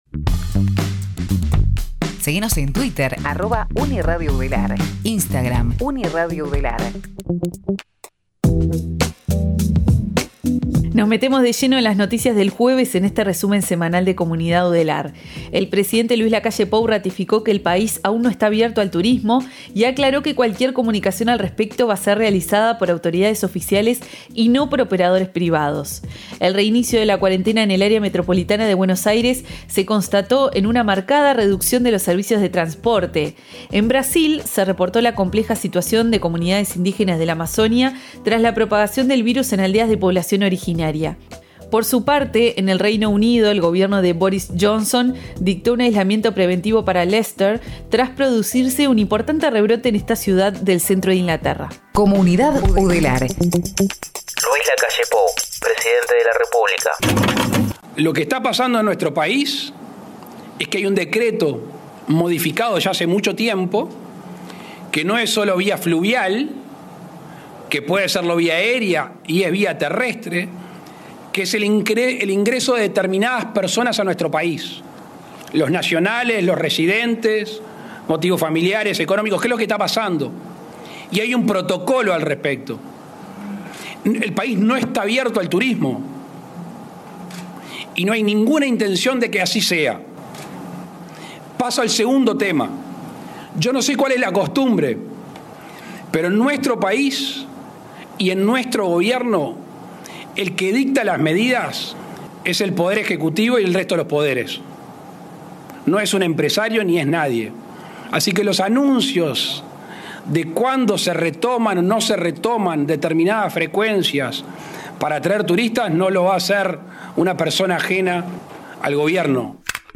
Compacto de noticias